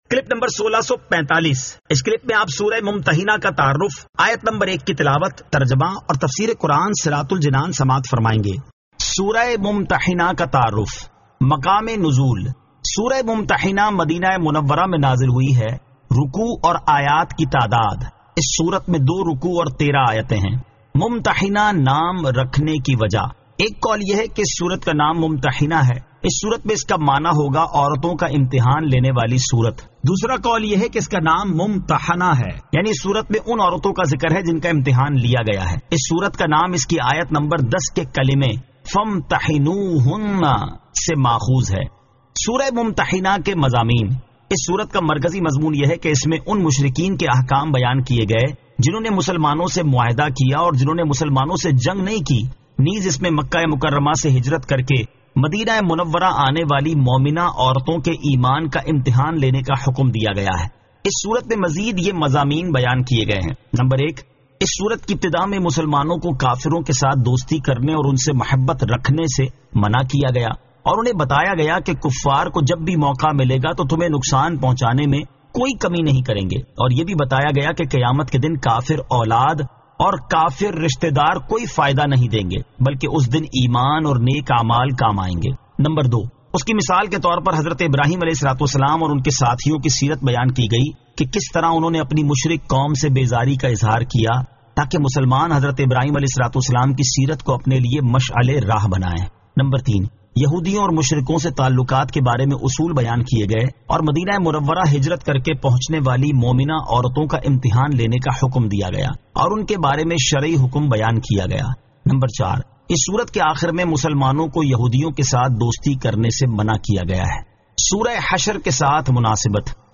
Surah Al-Mumtahinan 01 To 01 Tilawat , Tarjama , Tafseer